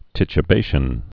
(tĭchə-bāshən)